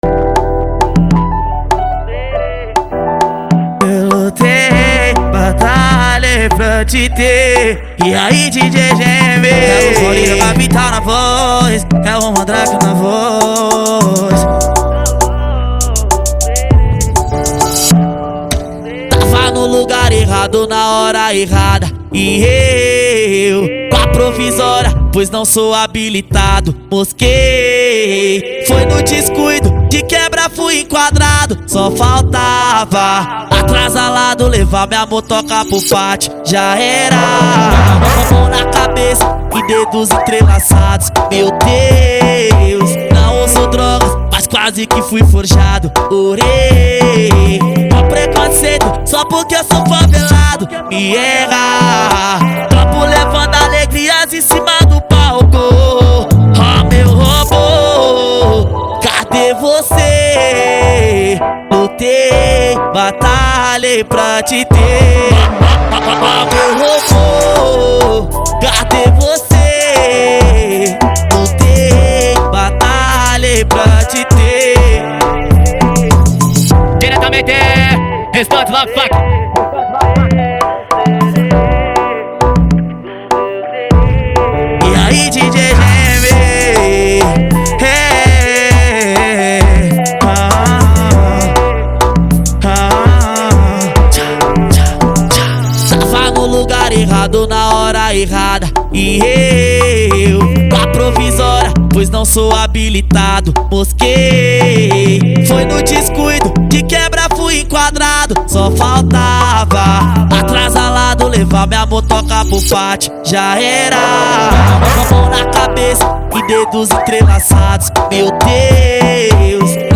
Baixar Funk Letras